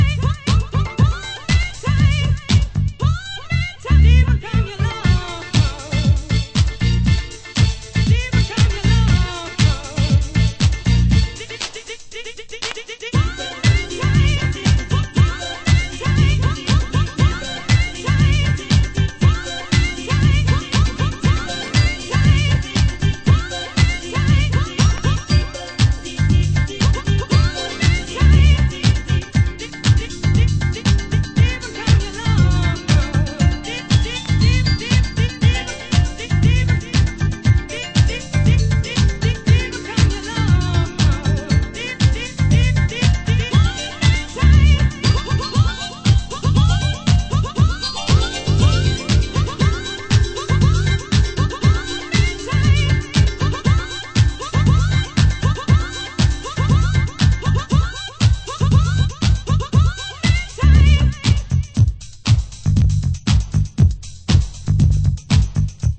盤質：盤面綺麗ですが少しチリノイズ有